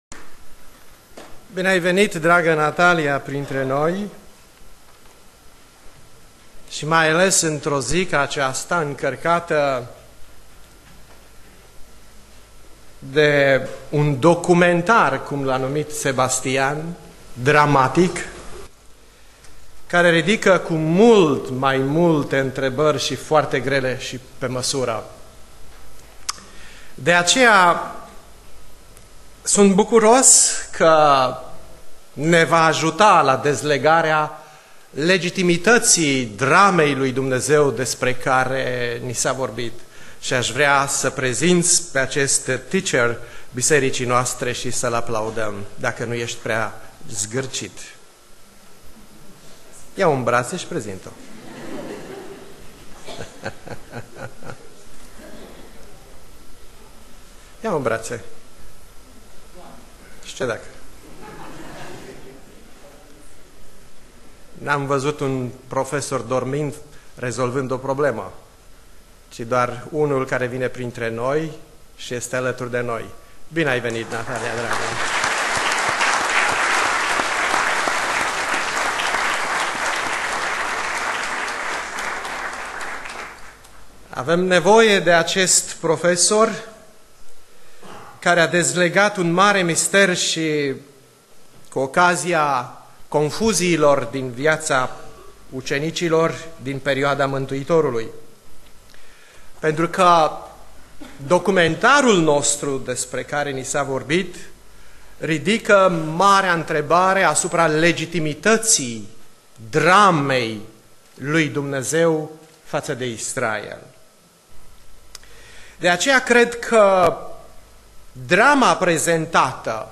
Predica Aplicatie - Ieremia cap. 2